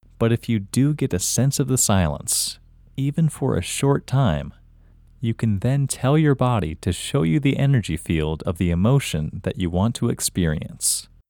LOCATE OUT English Male 34